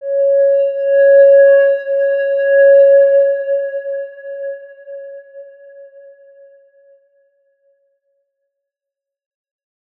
X_Windwistle-C#4-mf.wav